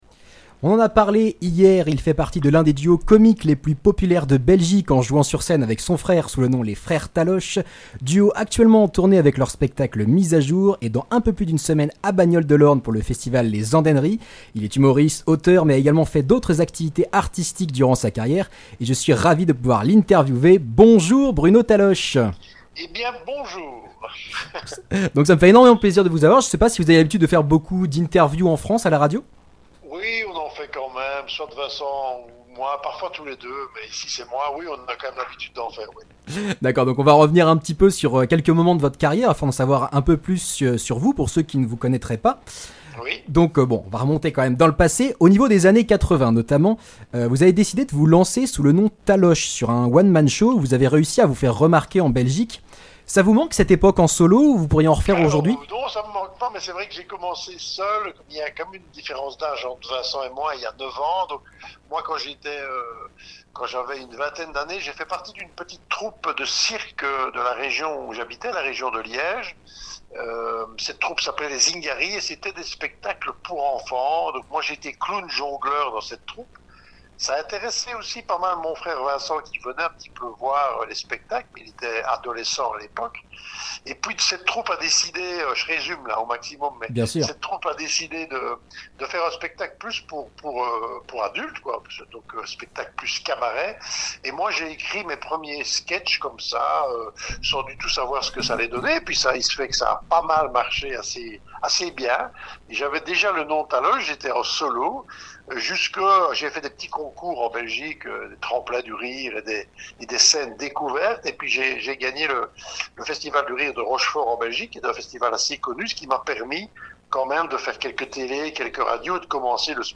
Interview avec Bruno Taloche (Les Frères Taloche)
interview-avec-bruno-taloche-les-freres-taloche.mp3